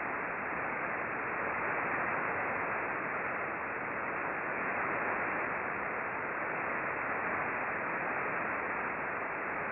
We used two Icom R-75 HF Receivers, one tuned to 19.962 MHz (LSB), corresponding to the Red trace in the charts below, and the other tuned to 19.448 MHz (LSB), corresponding to the Blue trace.
The antenna was an 8-element log periodic antenna pointed 244 degrees true (no tracking was used).
A 10 second stereo sound file of the L-bursts is provided below the charts.
Click here for a 10 second sound file of the L-bursts at approximately 1858